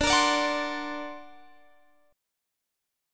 D+7 Chord
Listen to D+7 strummed